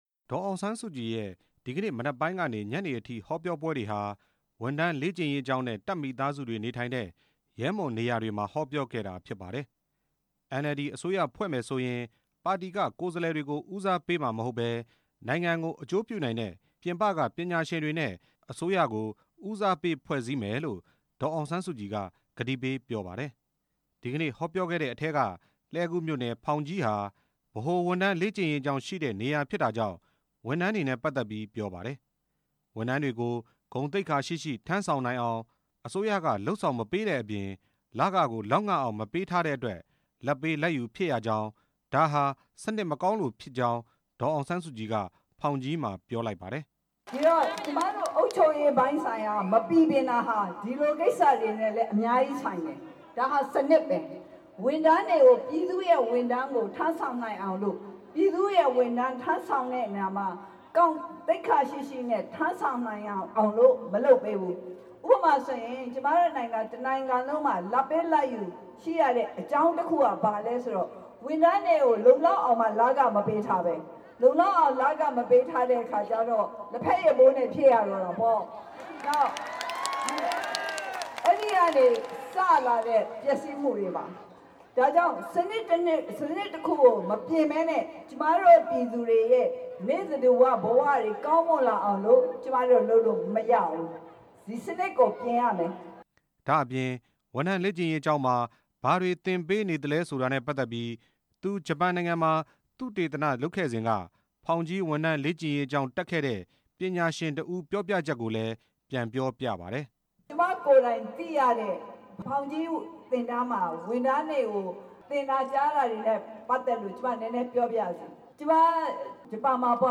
ဒေါ်အောင်ဆန်းစုကြည်ရဲ့ လှည်းကူးမြို့နယ်က ဟောပြောပွဲ တင်ပြချက်